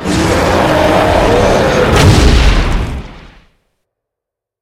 die.ogg